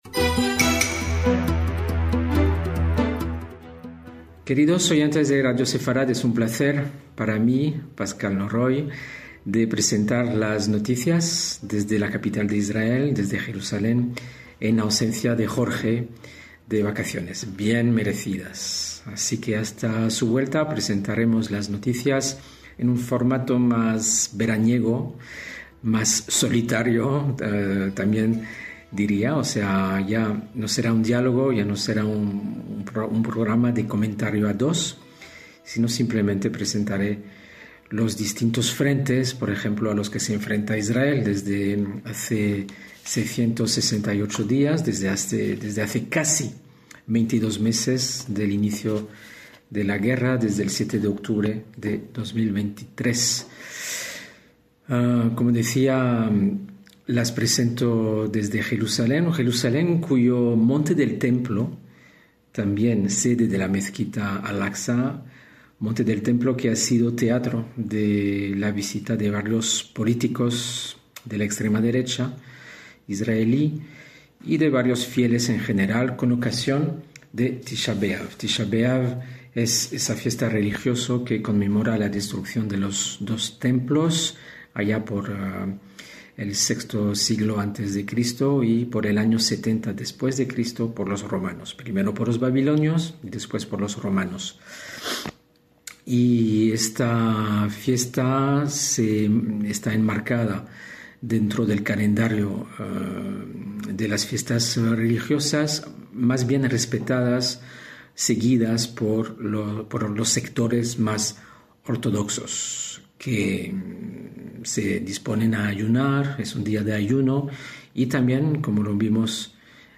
presenta y comenta las últimas noticias en torno a los distintos frentes a los que se enfrenta Israel.